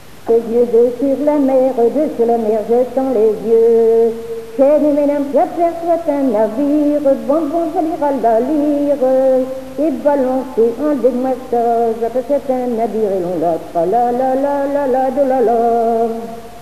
Saint-Lyphard
danse : quadrille
Pièce musicale inédite